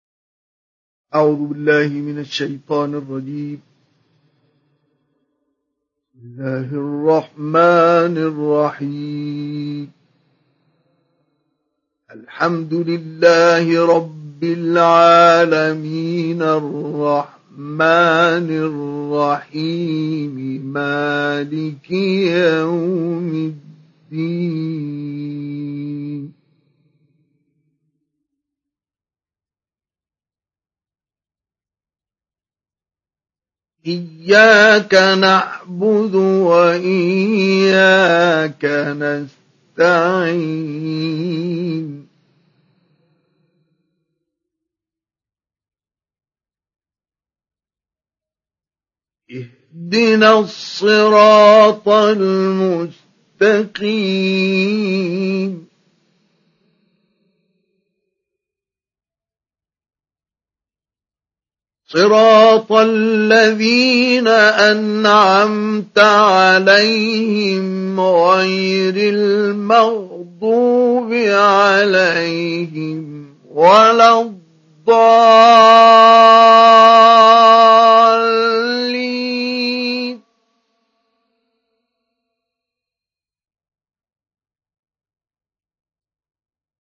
سُورَةُ الفَاتِحَةِ بصوت الشيخ مصطفى اسماعيل